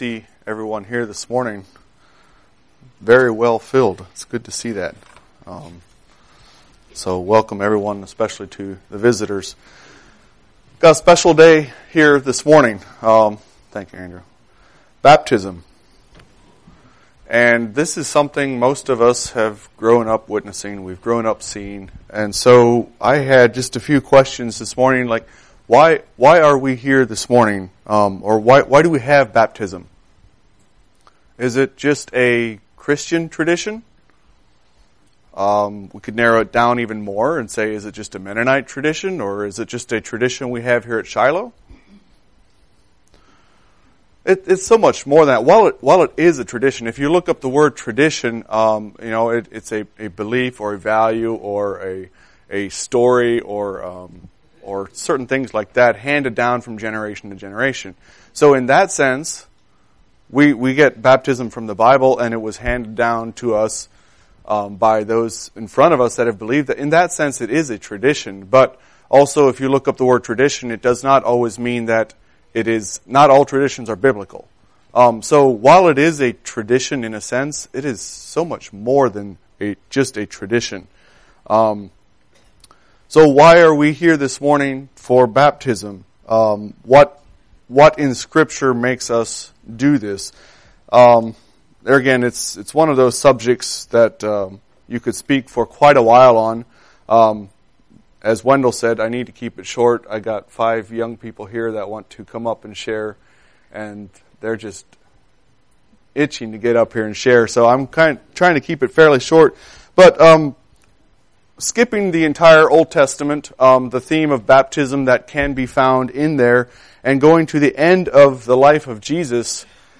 Sermons – Page 6 – Shiloh Mennonite